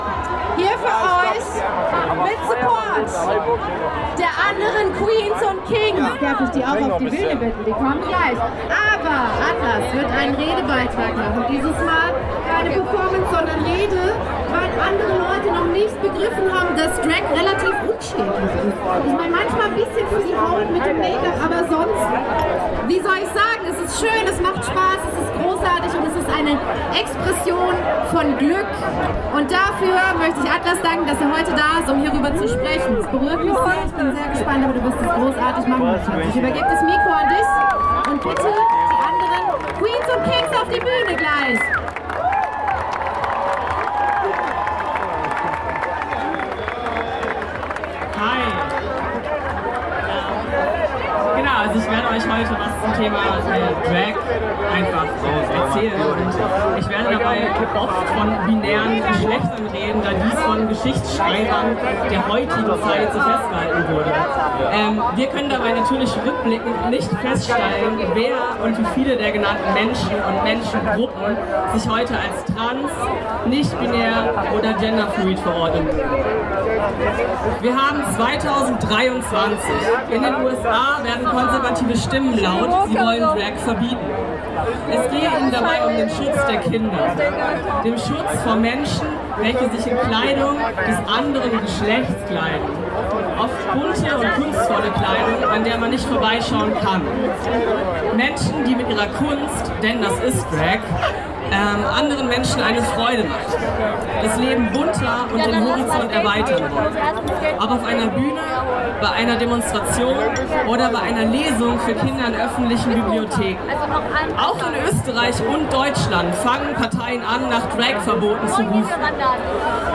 Redebeitrag